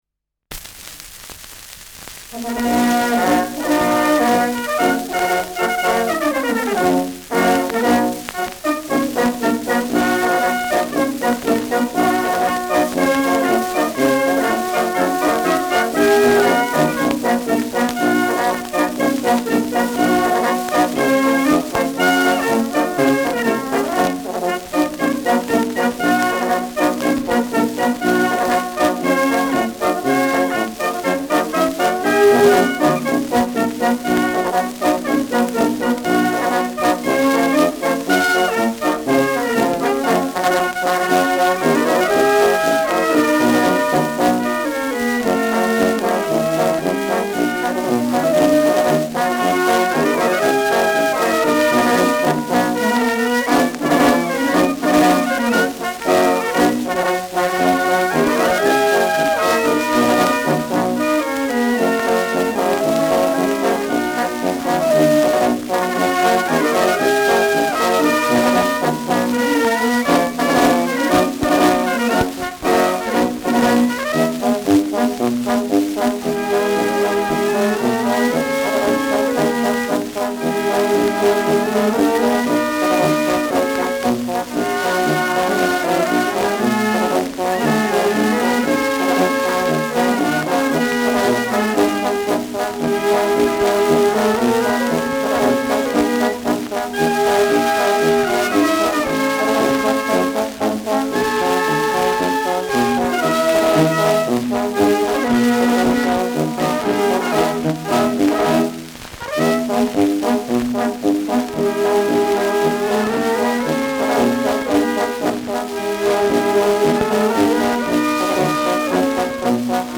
Schellackplatte
abgespielt : leichtes Rauschen : präsentes Knistern : leichtes „Schnarren“ : leiert : gelegentliches Knacken
Dachauer Bauernkapelle (Interpretation)